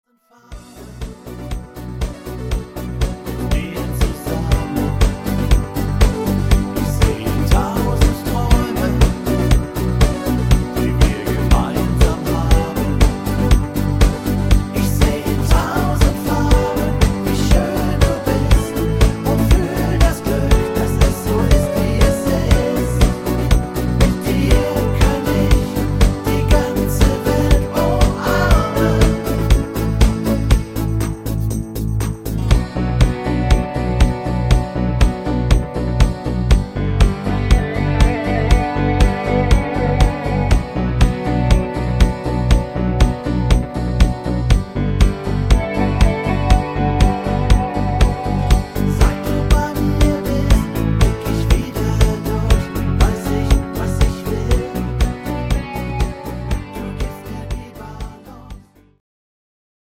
Discofox Version